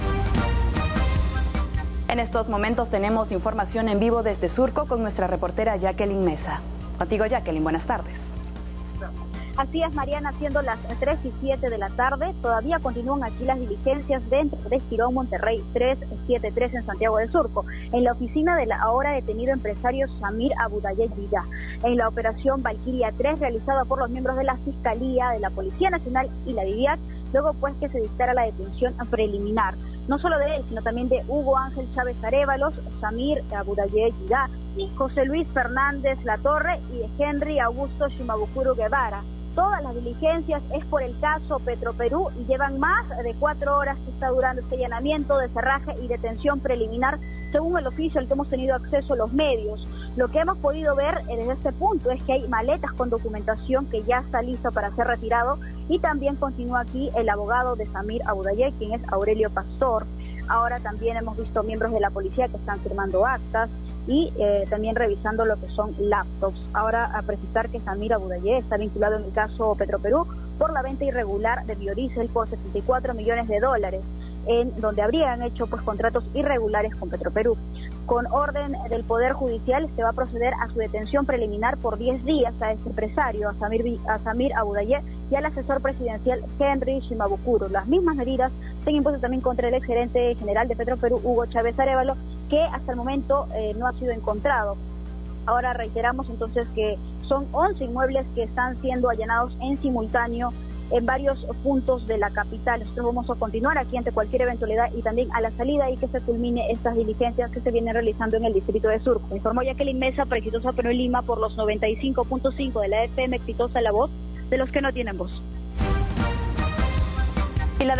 En vivo.